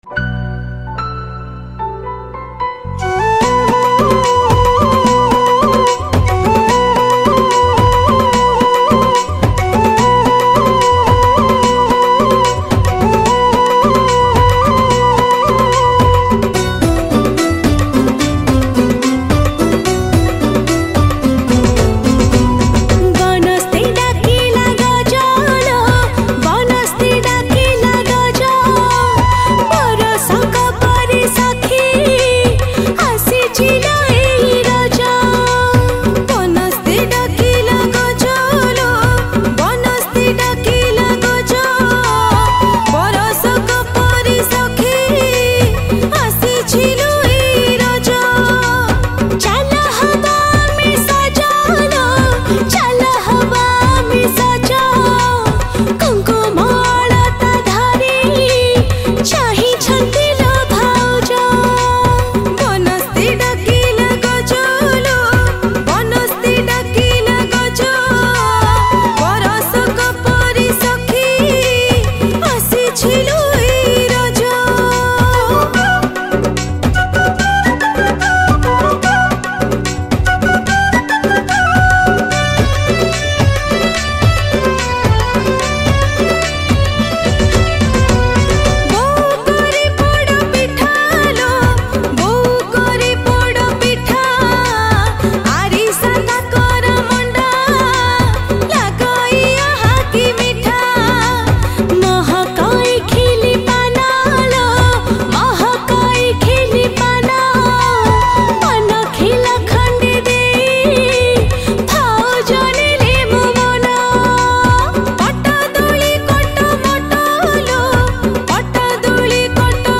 Keyboard
Rhythm